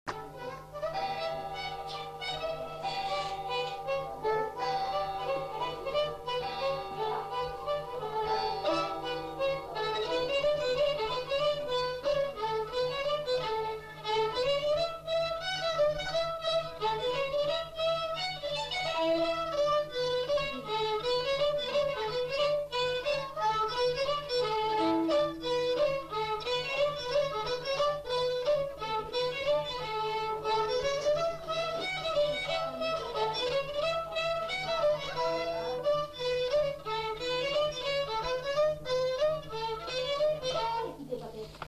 Lieu : Saint-Michel-de-Castelnau
Genre : morceau instrumental
Instrument de musique : violon
Danse : scottish double
Notes consultables : 2 violons.